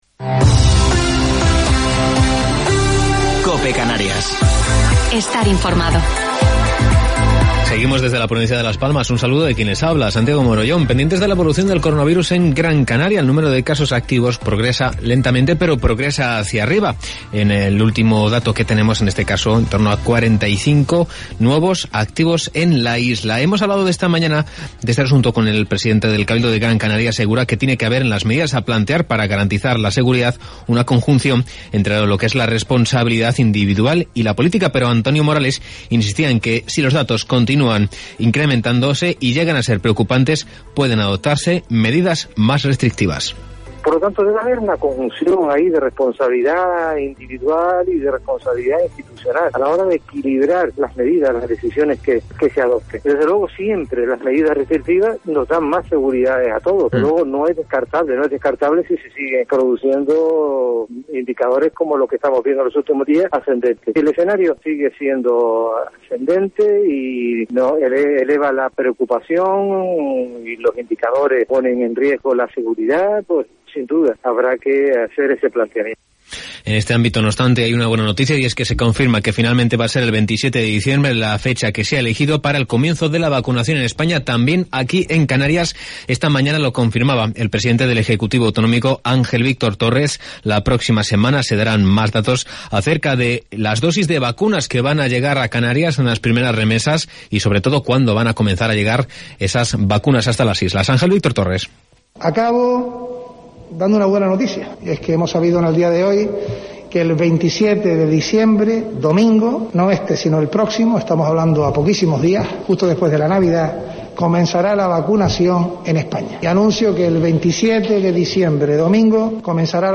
Informativo local 18 de Diciembre del 2020